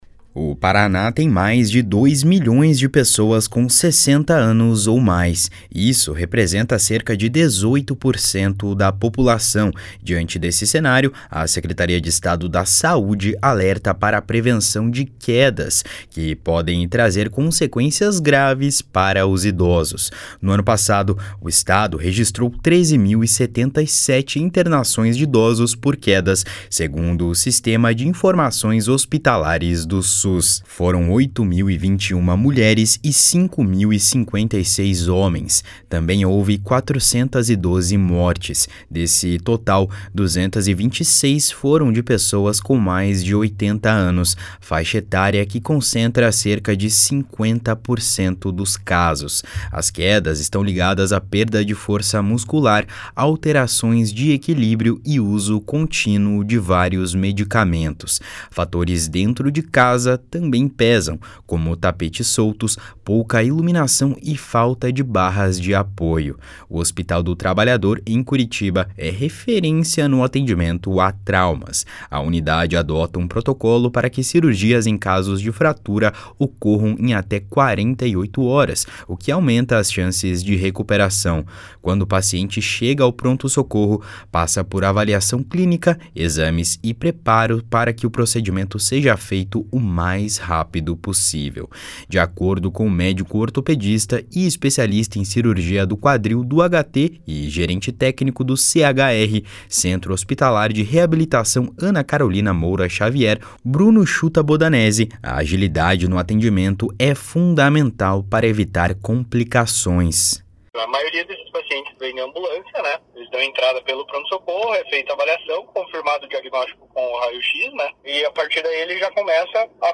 SAUDE ALERTA SOBRE OS RISCOS DE QUEDA DE IDOSOS.mp3